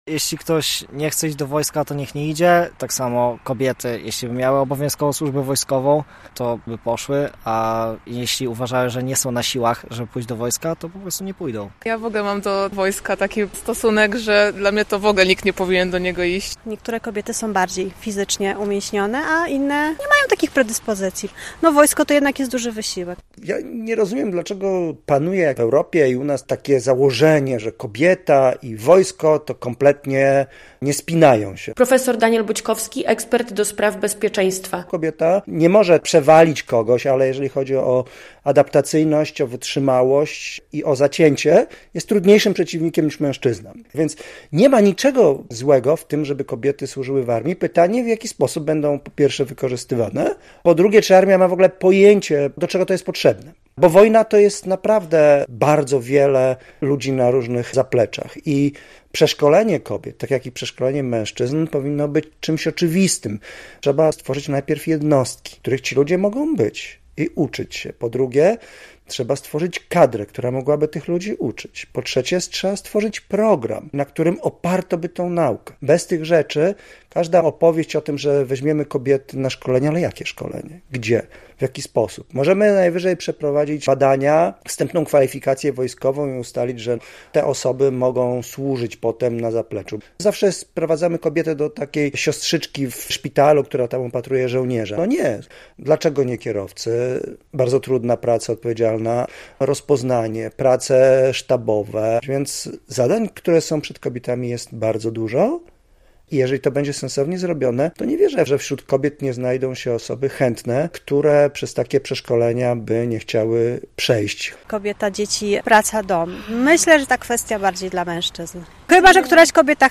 relacja
Odmienne zdanie ma część zapytanych przez Polskie Radio Białystok białostoczan.